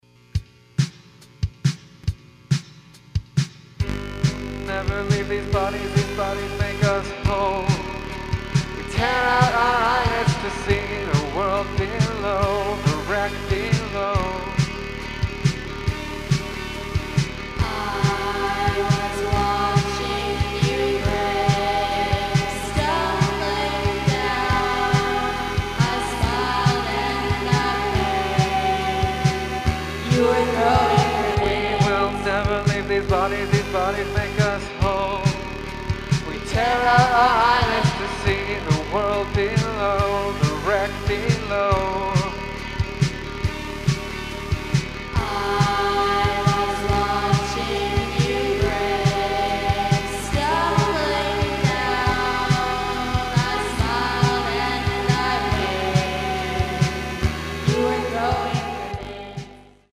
MunitionsVocals.mp3